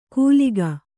♪ kūliga